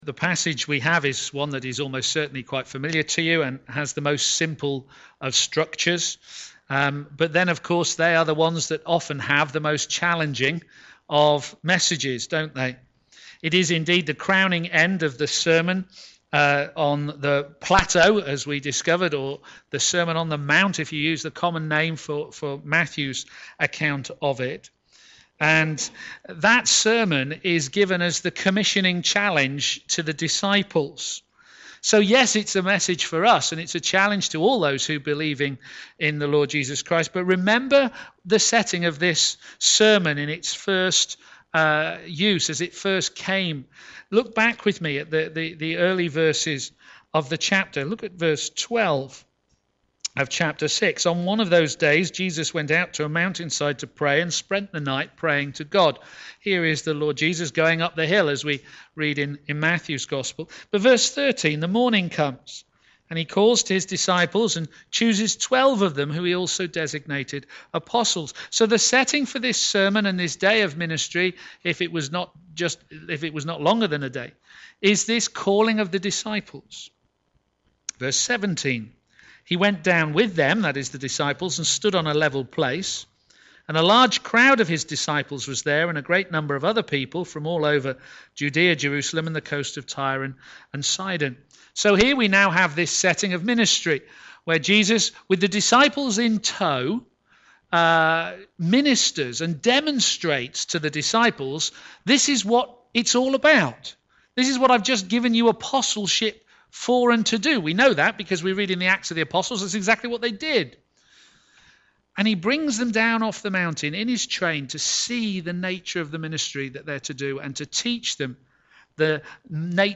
Media Library Media for a.m. Service on Sun 28th Aug 2016 10:30 Speaker
Theme: Do What I Say Sermon